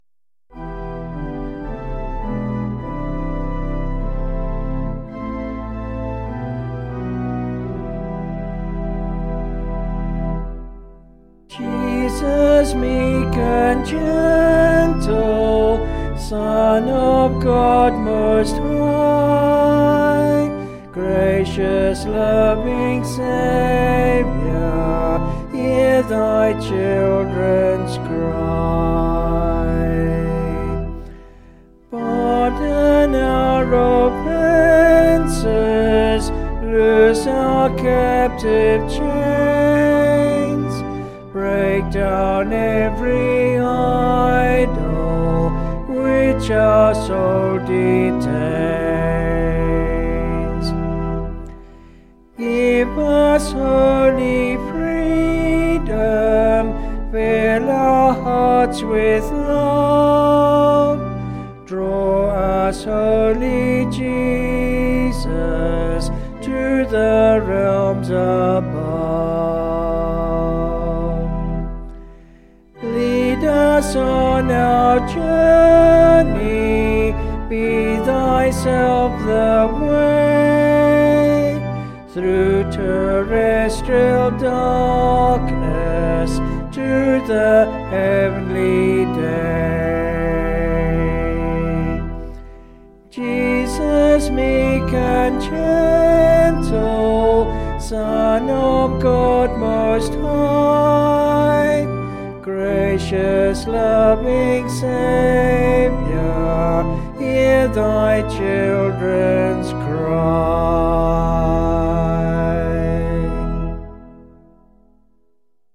(BH)   5/Em
Vocals and Organ   262.8kb Sung Lyrics